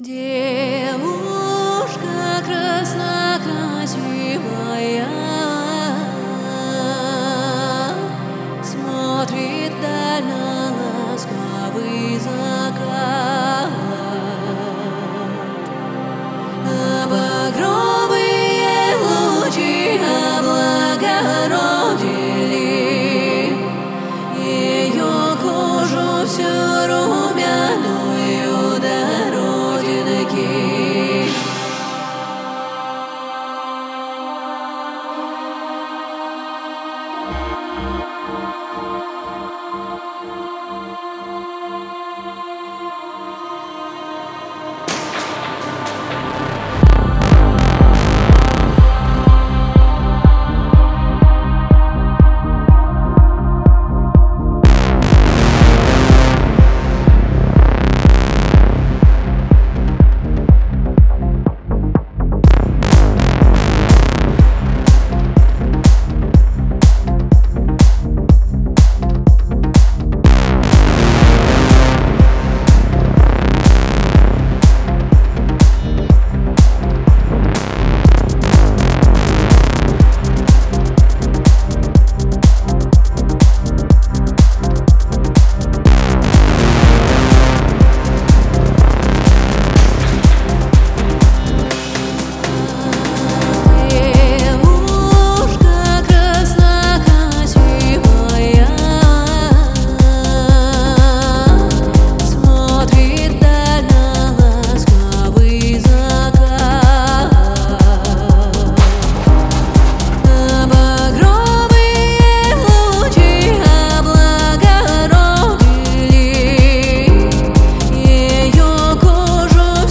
メロディックテクノ系では特に使い勝手も良く、 重宝するサンプルパックです。
Genre:Melodic Techno
78 Female Vocal Loops